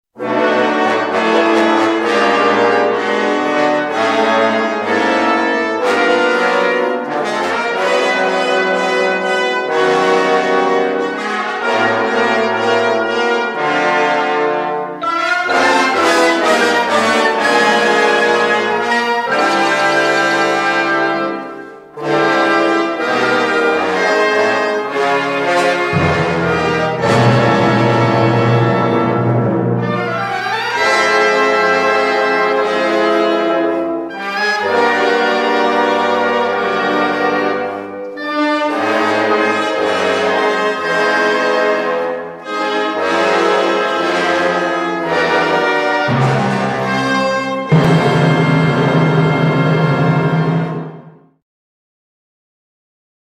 Cantata for:
Modern piece for woodwind and brass